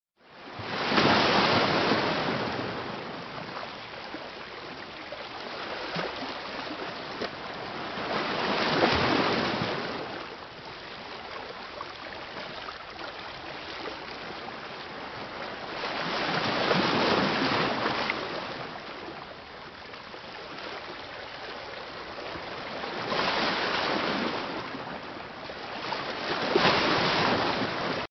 ocean-waves_24815.mp3